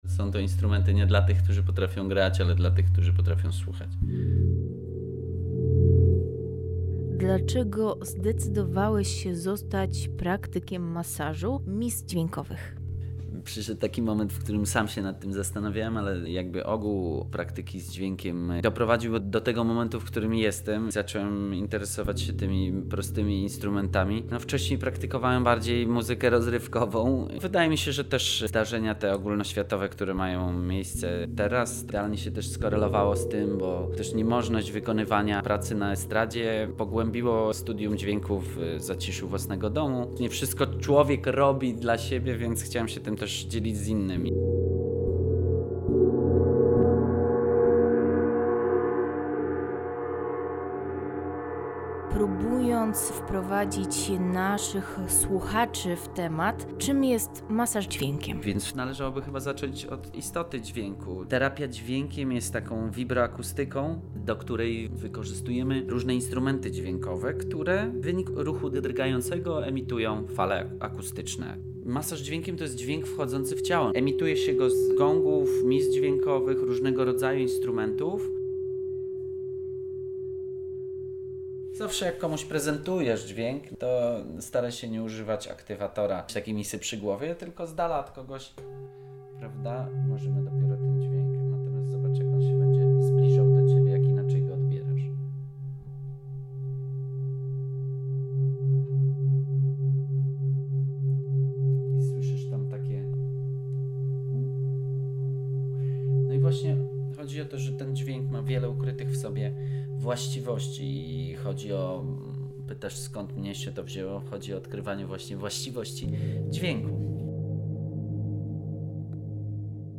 Wszystko za sprawą specjalnych mis, które za pomocą filcowych pałeczek uwalniają wewnętrzne, muzyczne piękno.
Masaz-dzwiekiem.mp3